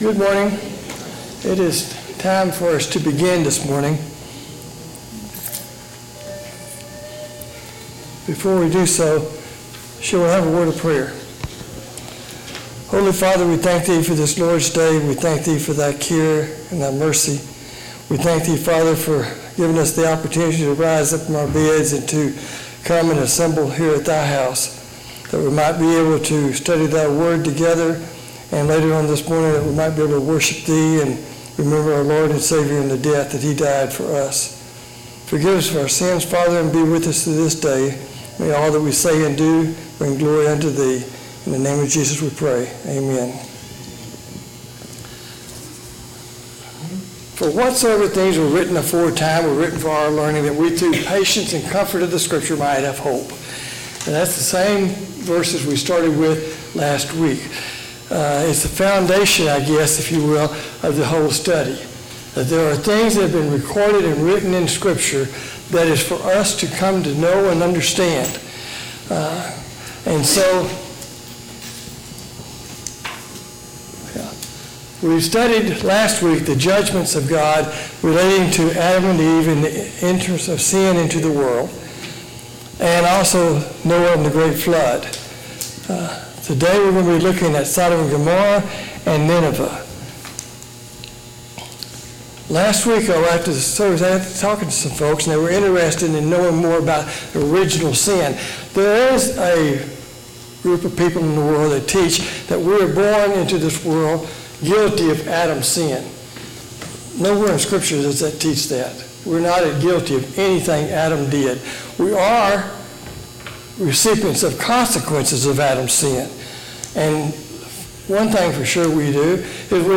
Judgements of God Service Type: Sunday Morning Bible Class Download Files Notes Topics